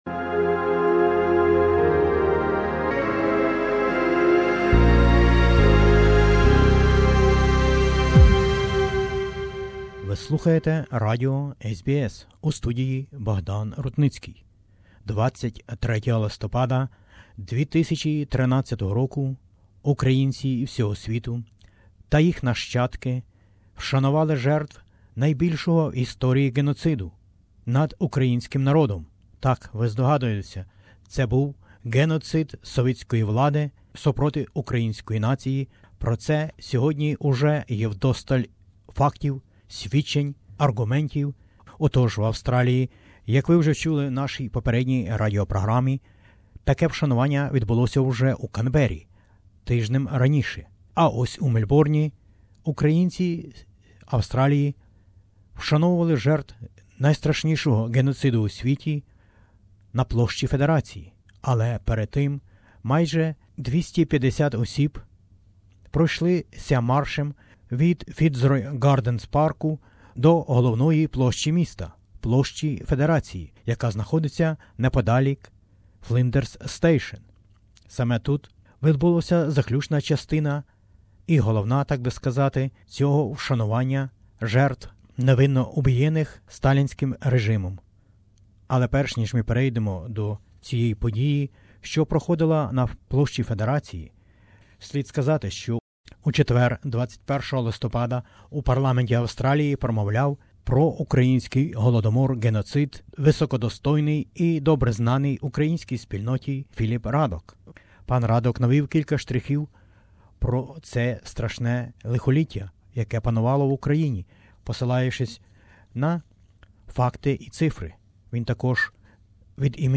speech…